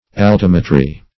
Altimetry \Al*tim"e*try\, n. [Cf. F. altim['e]trie.]